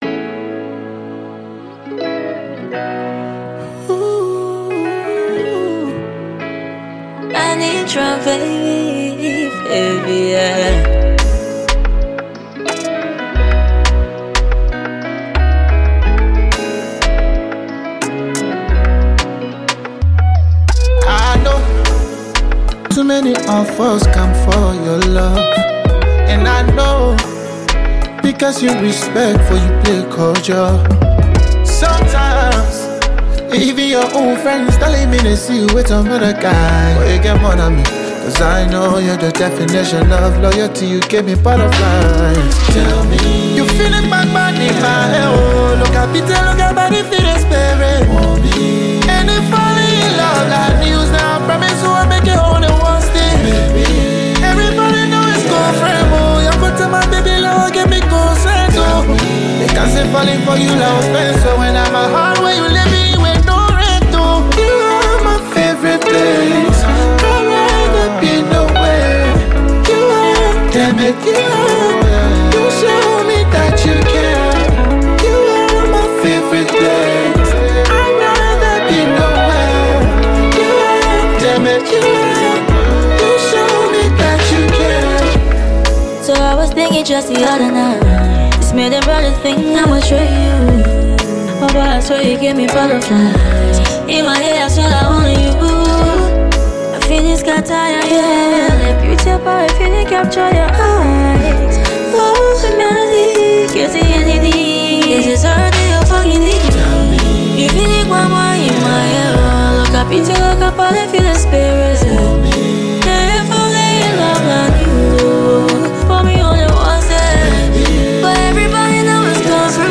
A mixture of RnB with a fusion of Liberian colloquialisms.